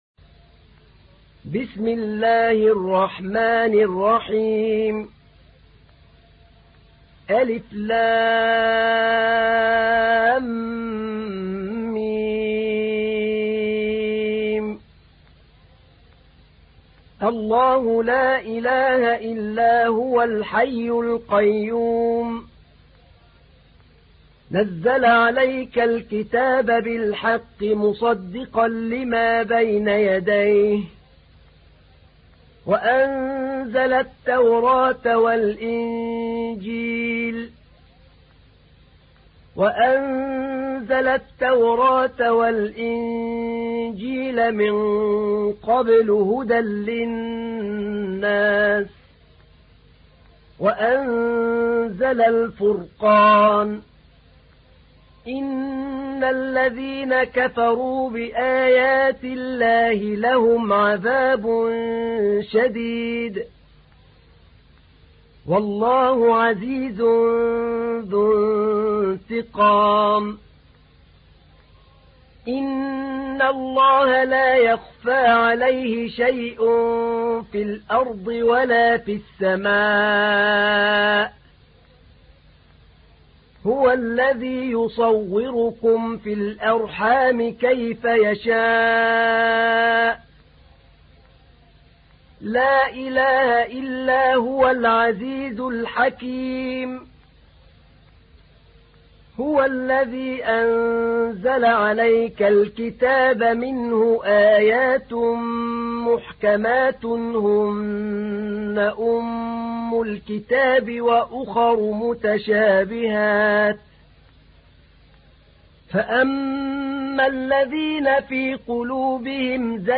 تحميل : 3. سورة آل عمران / القارئ أحمد نعينع / القرآن الكريم / موقع يا حسين